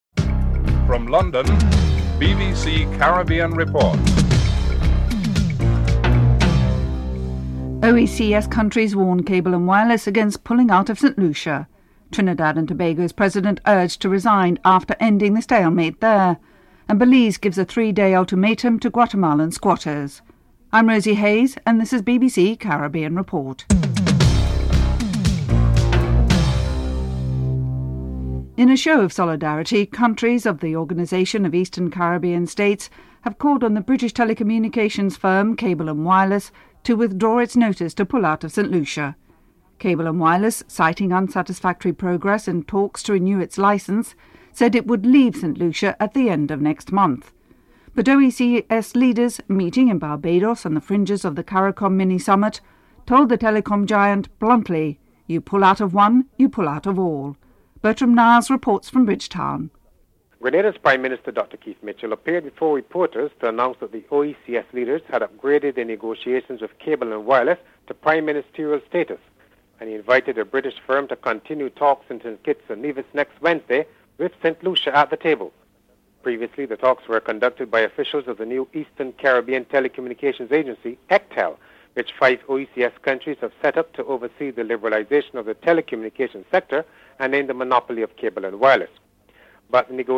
1. Headlines (00:00-00:27)
2. OECS countries warned Cable & Wireless against pulling out of St. Lucia. Chairman of the OECS Authority Dr. Keith Mitchell is interviewed.